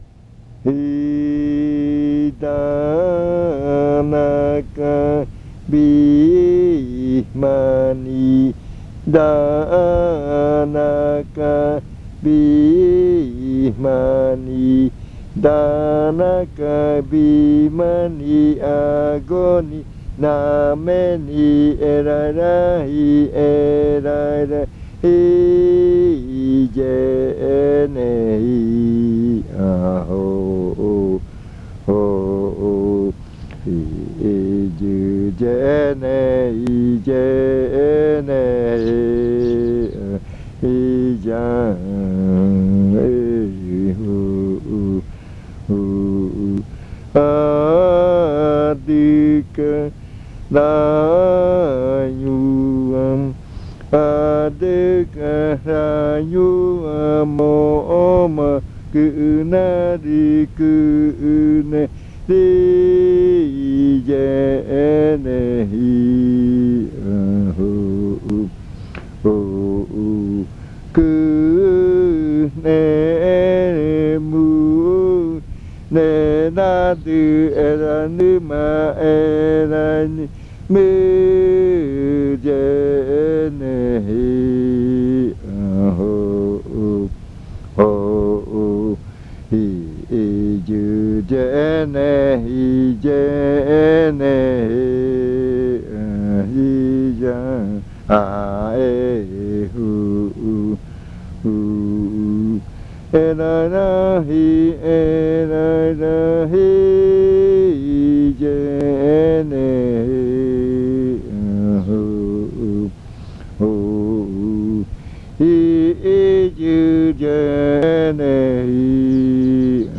Leticia, Amazonas
Canto de arrimada. La desembocadura del río mar.
Entry chant.
This chant is part of the collection of chants from the Yuakɨ Murui-Muina (fruit ritual) of the Murui people